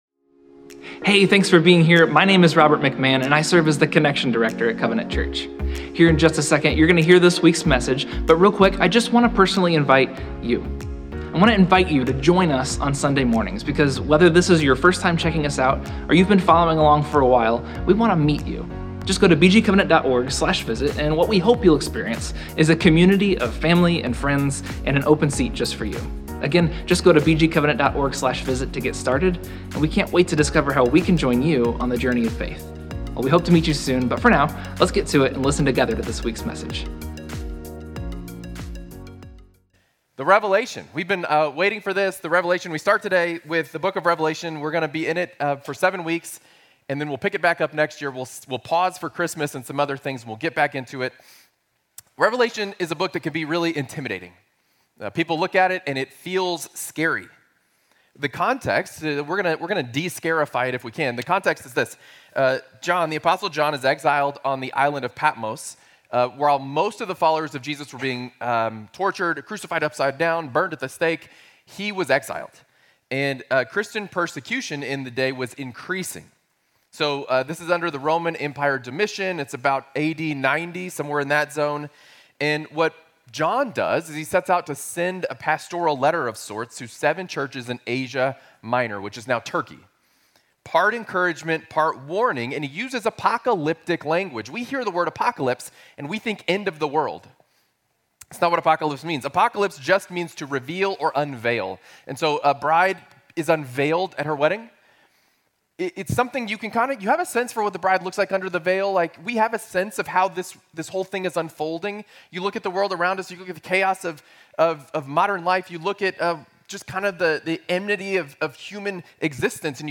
Sermons | Covenant Church